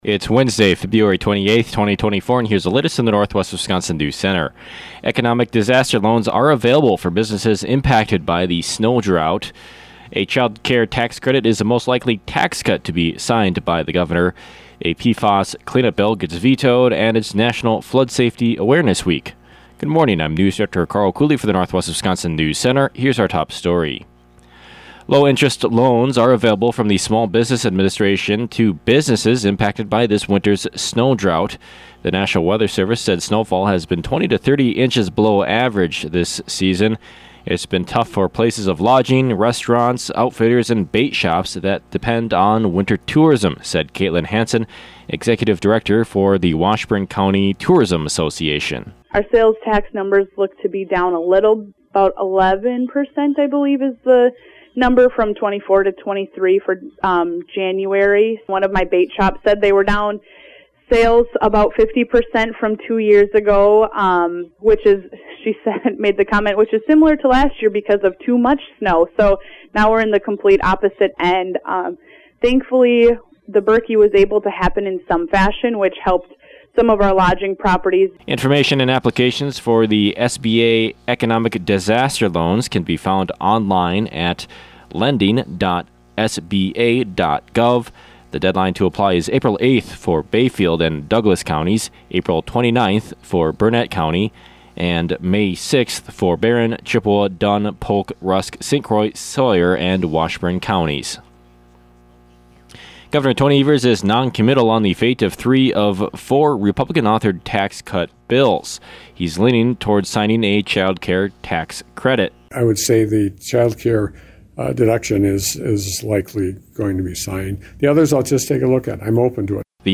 AM NEWSCAST – Wednesday, Feb. 28, 2024 | Northwest Builders, Inc.